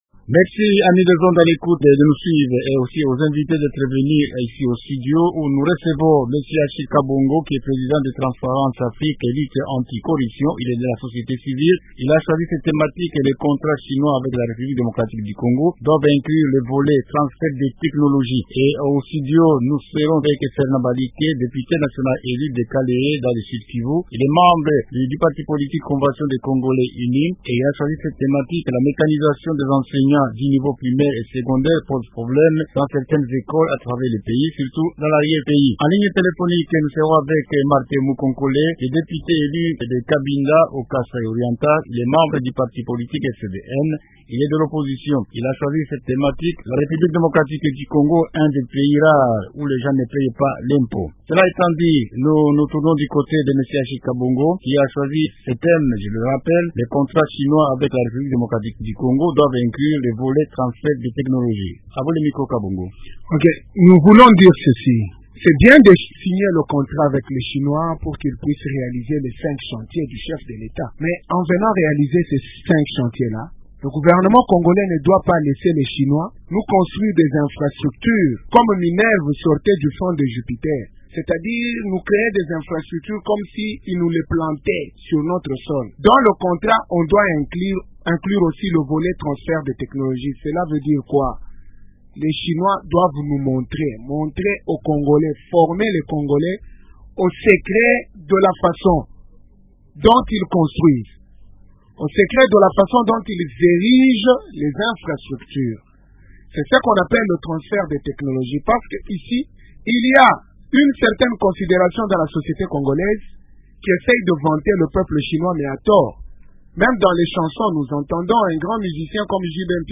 Les contrats Chine-RDC doivent inclure le transfert des technologies Chinoises, le non paiement des taxes et la mécanisation des enseignants en RDC .Ce sont là les thèmes du débat de ce soir.
- Fernand Baliki , député national du parti politique du Convention des Congolais Uni (CCI),